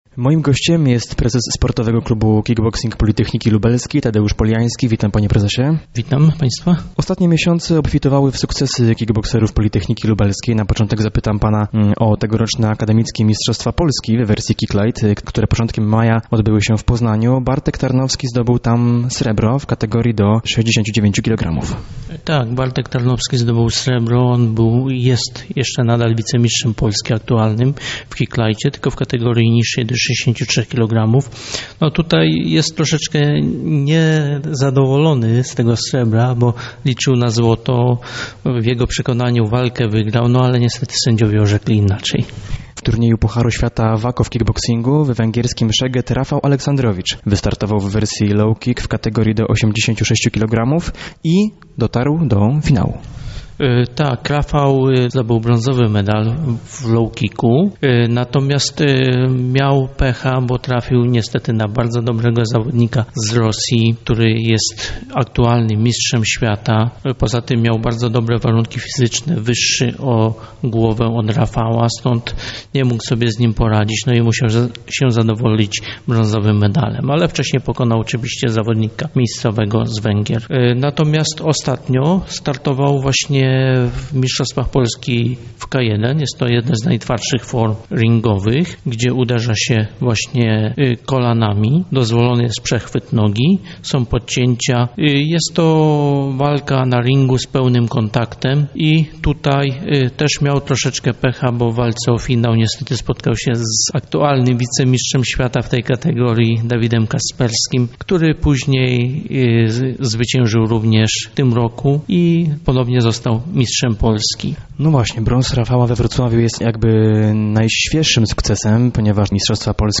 Poniżej rozmowa